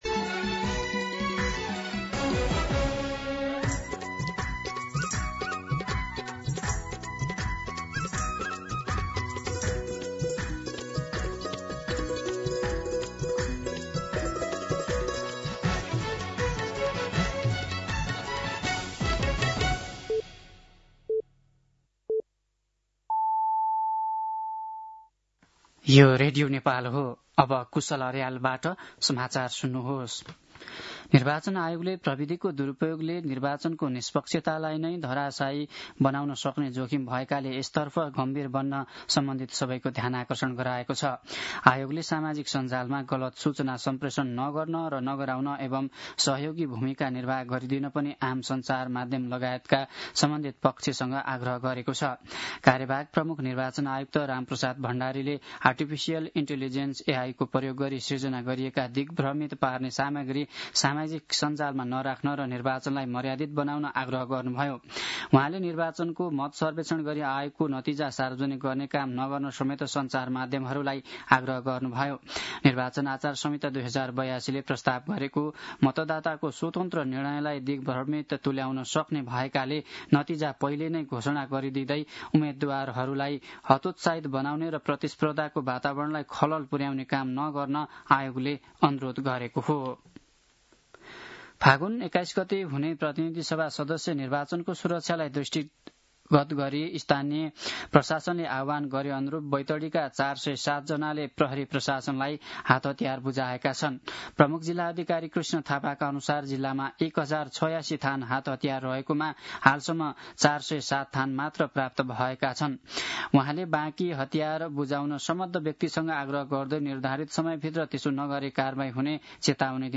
दिउँसो ४ बजेको नेपाली समाचार : ६ फागुन , २०८२
4-pm-Nepali-News-2.mp3